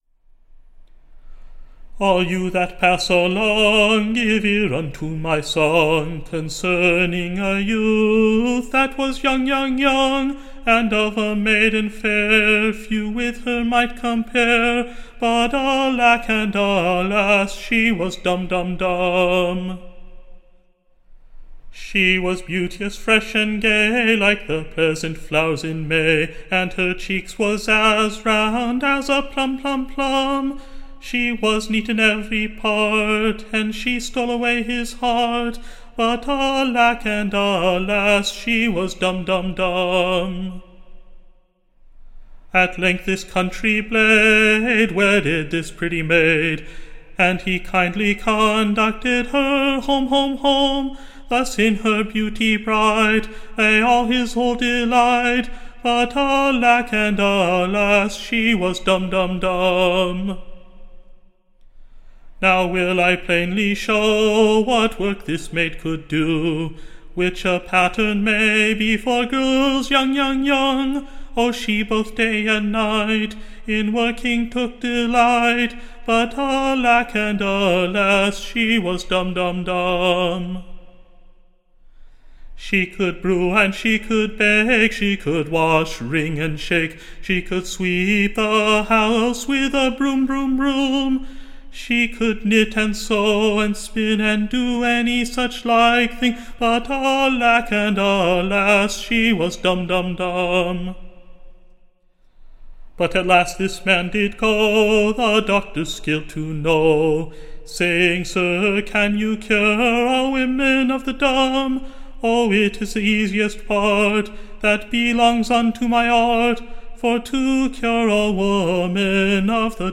Recording Information Ballad Title The Dumb Maid, / Or, The Young Gallant Trappand.